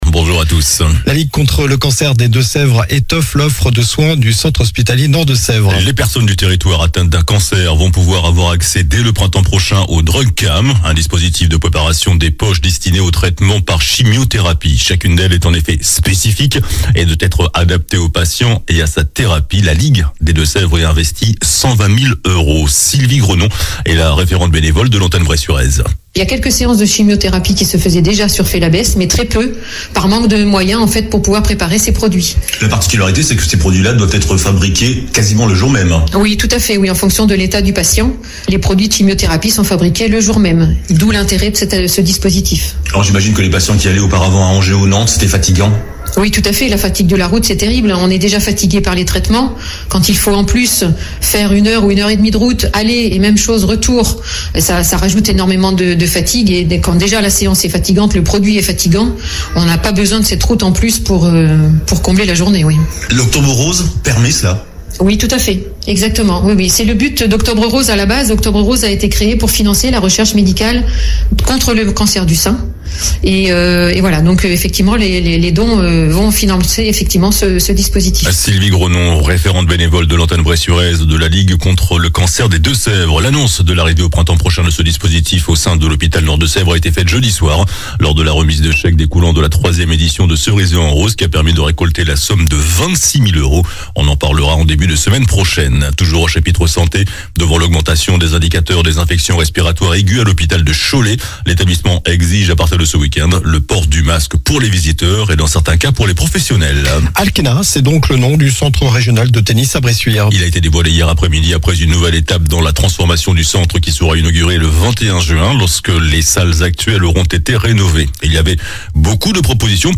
JOURNAL DU SAMEDI 21 DECEMBRE